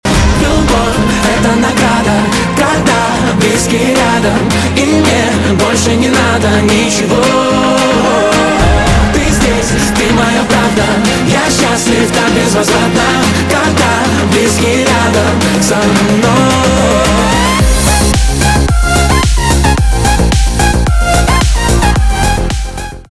поп
битовые, нарастающие, добрые